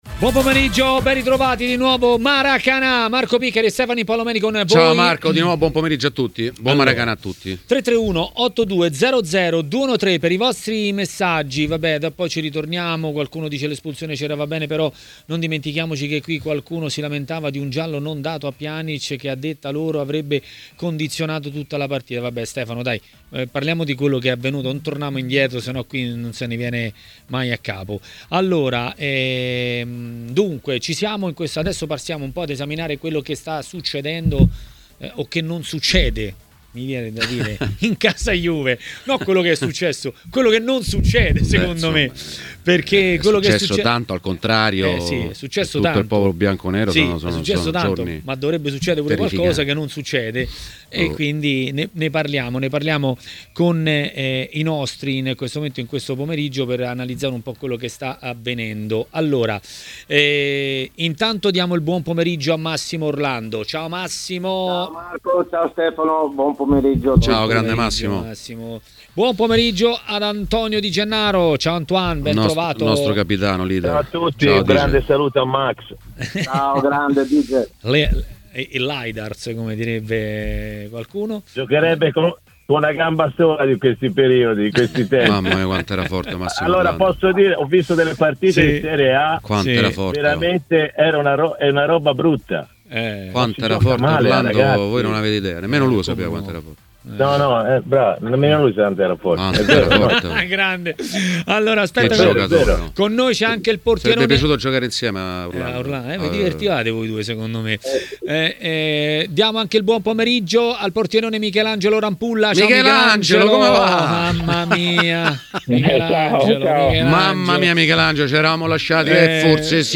A Maracanà, nel pomeriggio di TMW Radio, è arrivato il momento dell'ex calciatore Massimo Orlando.